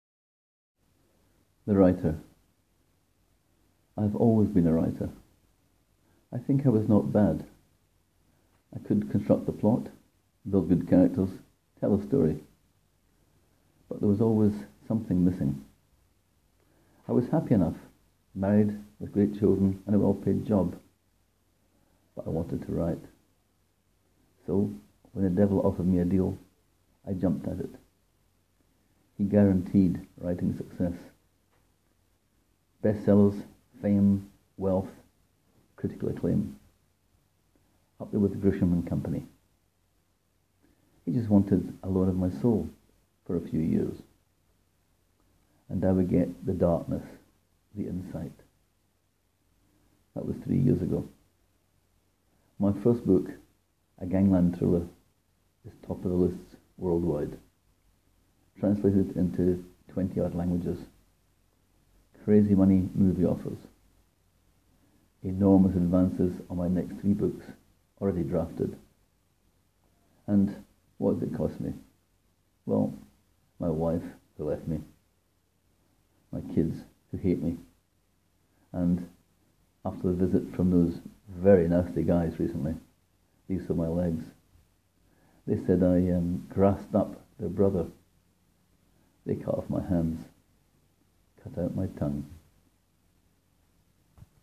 Click here to hear me read my story: